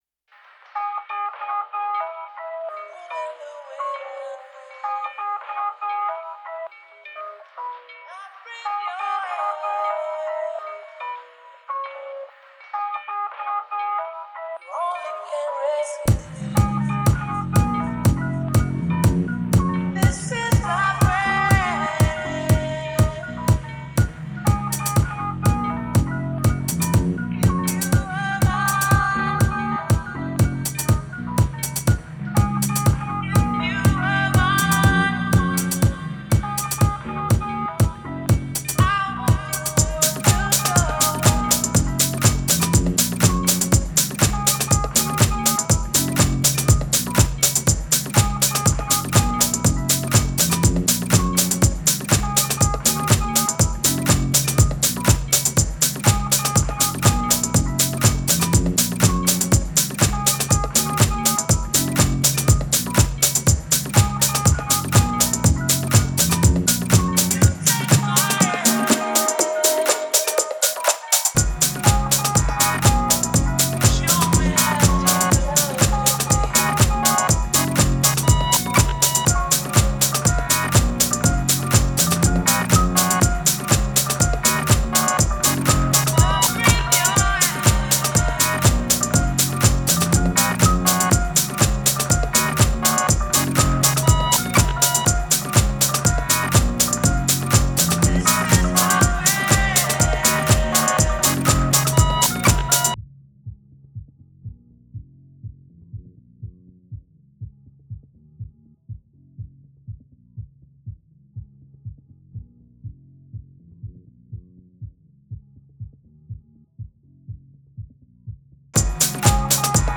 Just adding that all the samples are from the track minus the drums and the lovely Sade.
That drum beat is sick and I can see why you spent so long nursing this :v: :raised_hands:
That Sade sample is probably from early youtube days when people used to diy acapellas, so the quality was terrible to begin with but it was working for me at the moment.